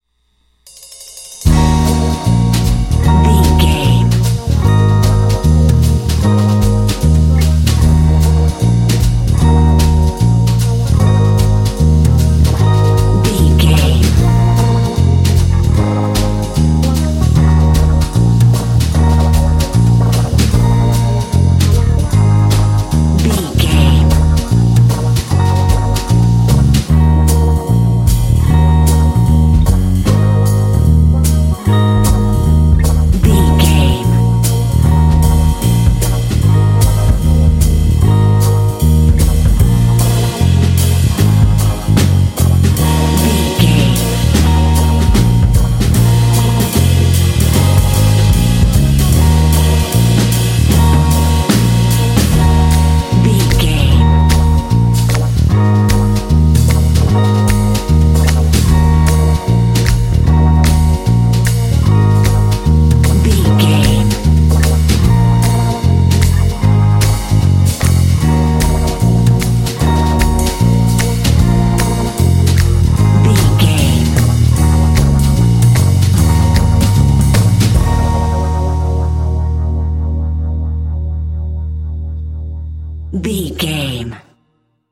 Aeolian/Minor
E♭
dreamy
optimistic
uplifting
bass guitar
drums
electric guitar
synthesiser
jazz
swing